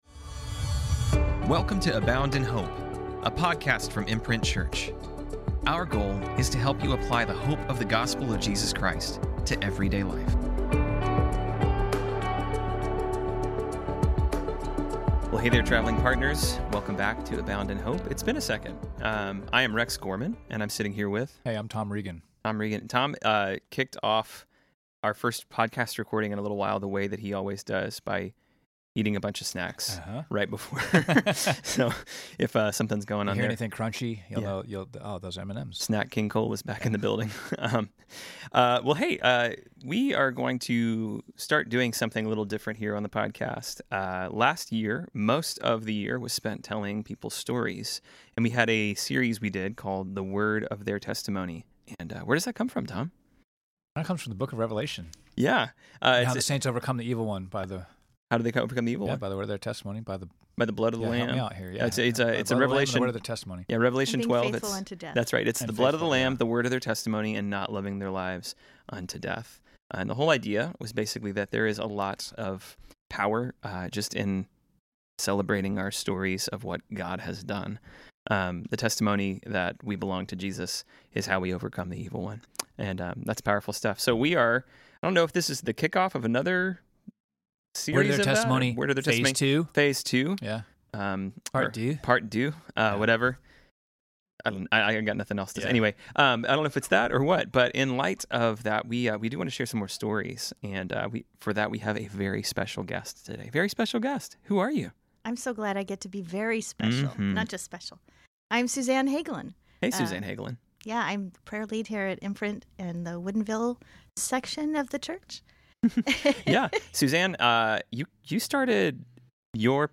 In our first conversation with her, we talk about living abroad, tragedy, and how the Lord helped her find belonging.